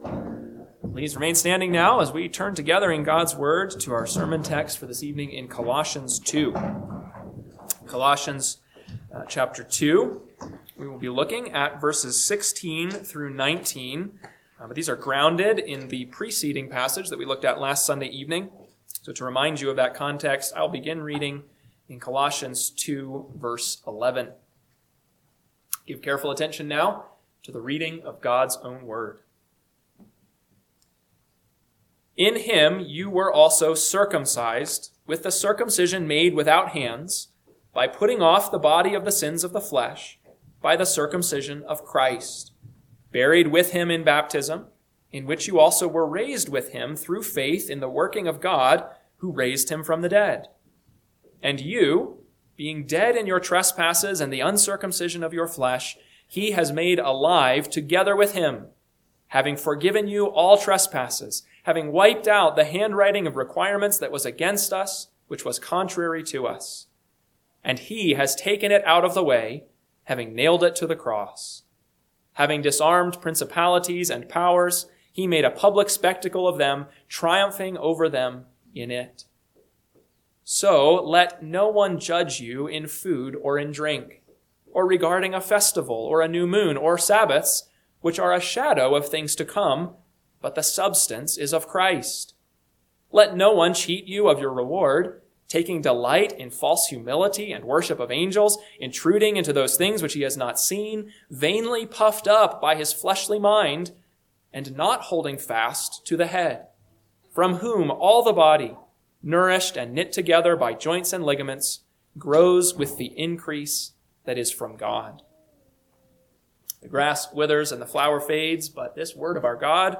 PM Sermon – 3/8/2026 – Colossians 2:16-19 – Northwoods Sermons